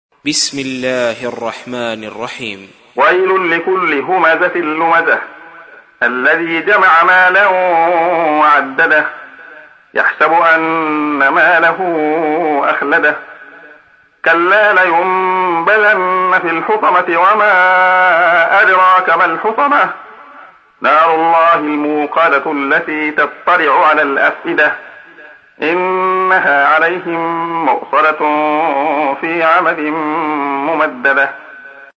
تحميل : 104. سورة الهمزة / القارئ عبد الله خياط / القرآن الكريم / موقع يا حسين